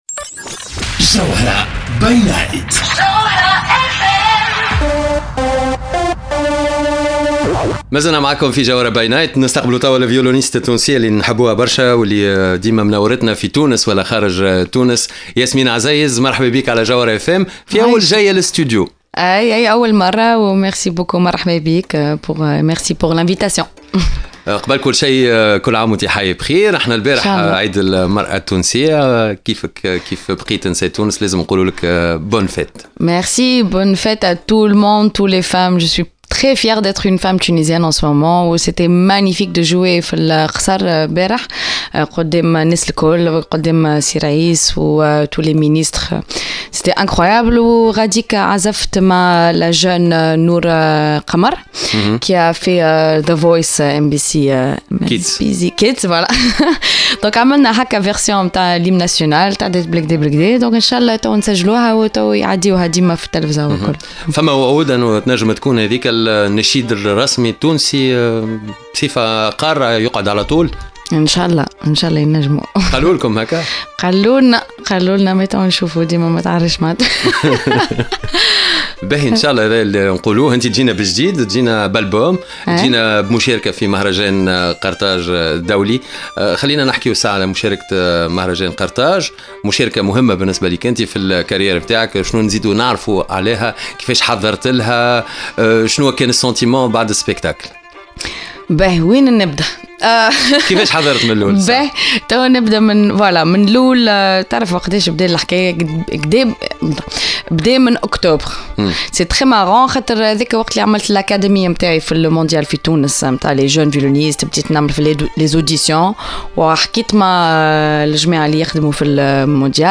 ضيفة الجوهرة "اف ام"